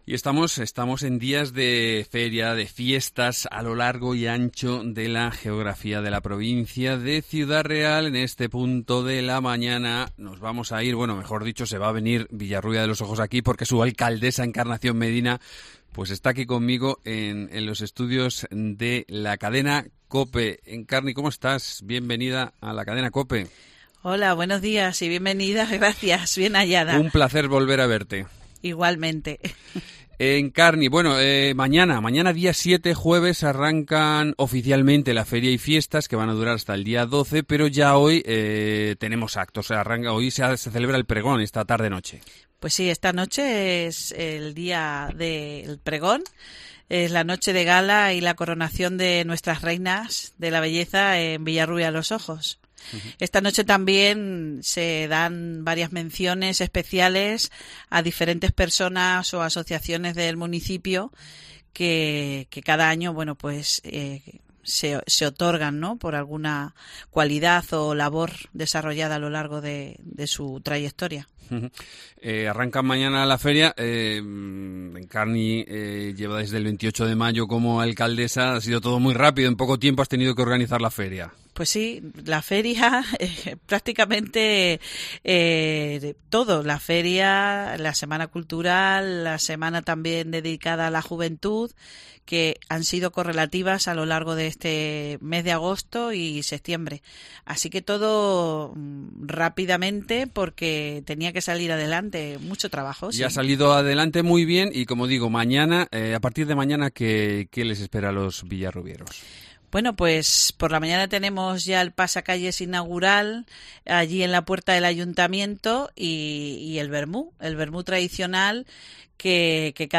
Encarnación Medina, alcaldesa de Villarrubia de los Ojos